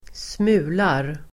Uttal: [²sm'u:lar]